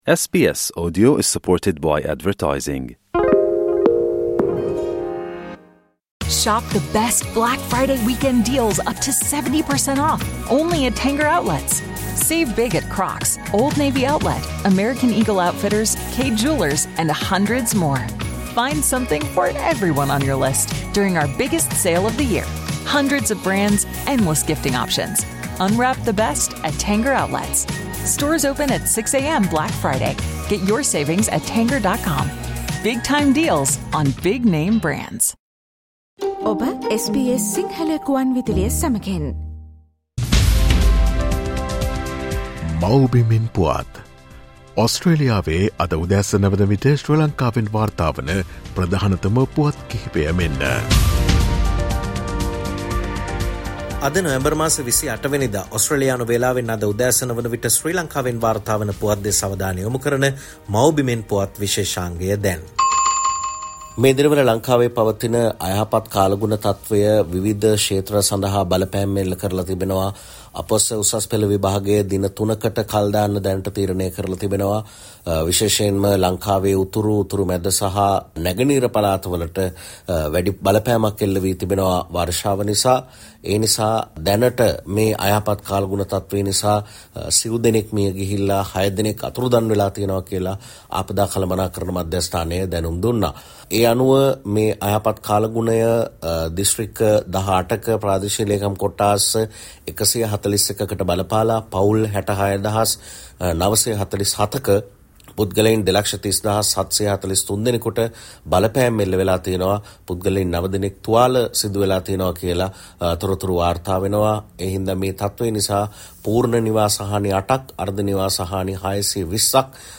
'The new government agreed to reschedule the IMF loan 2 days before the election' says Harsha: Homeland news